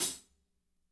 -18  CHH B-L.wav